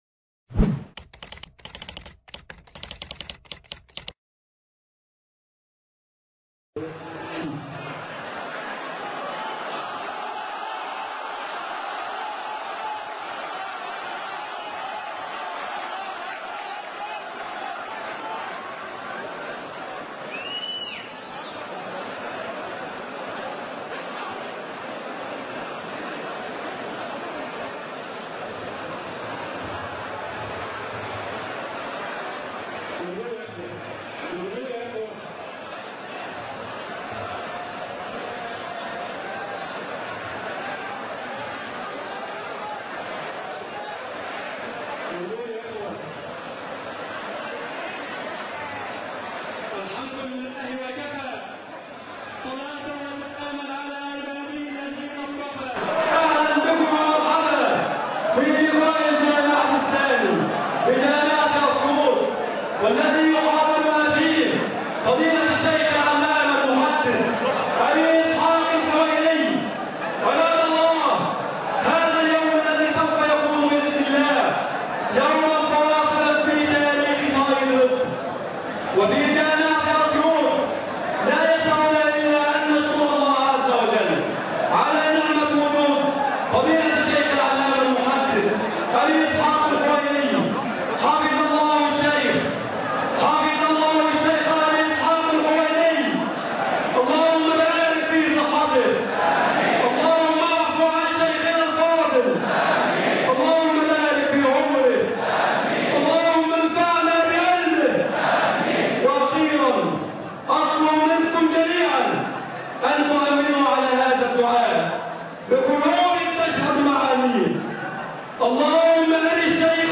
محاضرة فضيلة الشيخ أبو إسحاق الحويني بجامعة أسيوط - الشيخ أبو إسحاق الحويني